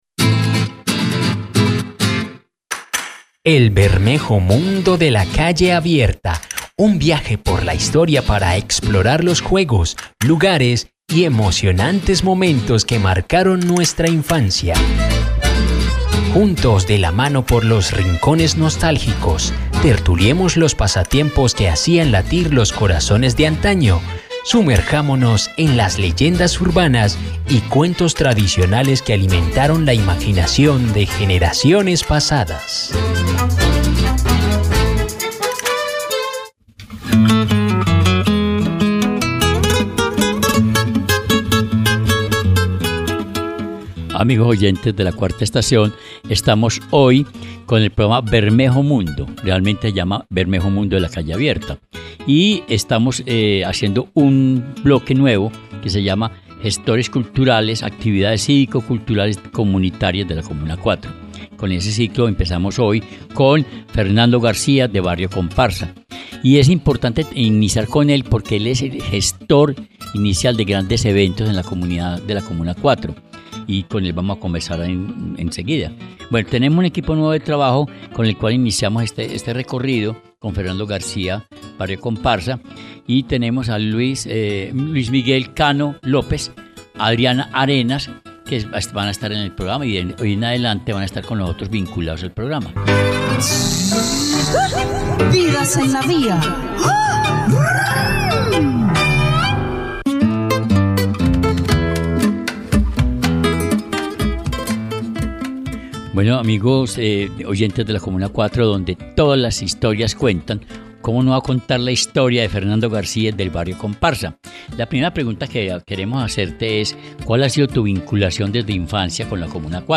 Una charla íntima, poderosa y llena de memoria viva.